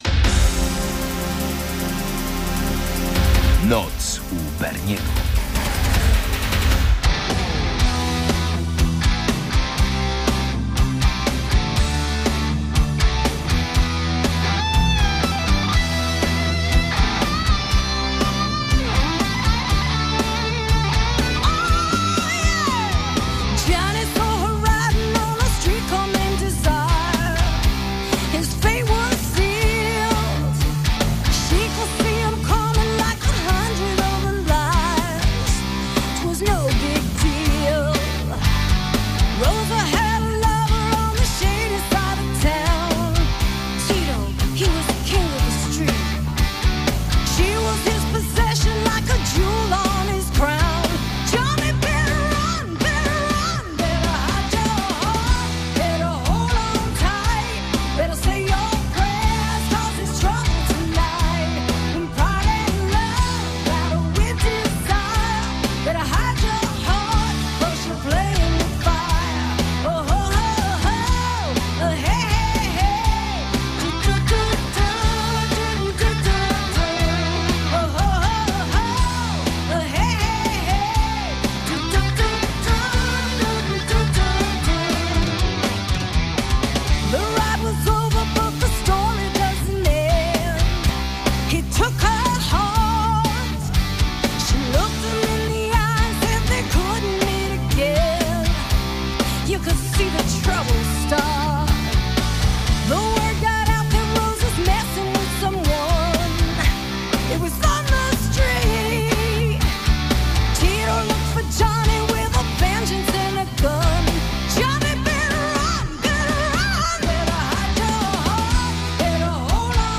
Dominujący gatunek: heavy metal i hard rock